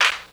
• Clap One Shot F# Key 28.wav
Royality free hand clap - kick tuned to the F# note. Loudest frequency: 2547Hz
clap-one-shot-f-sharp-key-28-cr0.wav